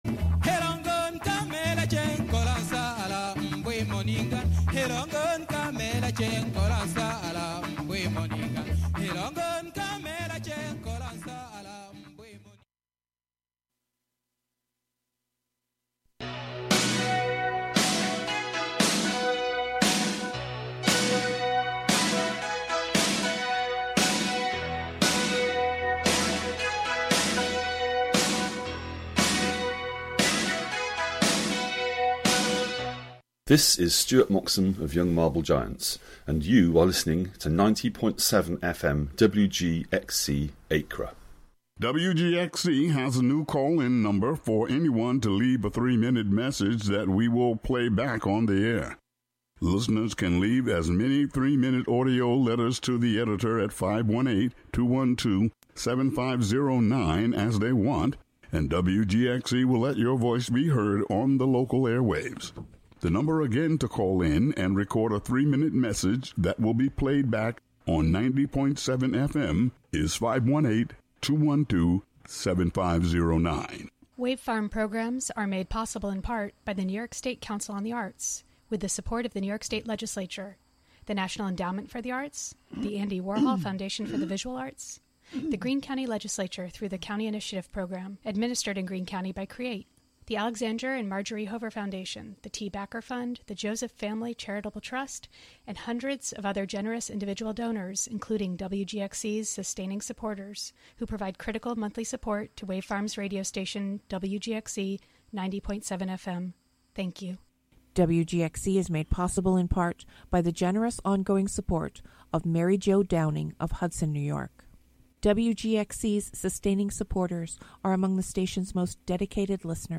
An upbeat music show featuring the American songbook
jazz greats in a diverse range of genres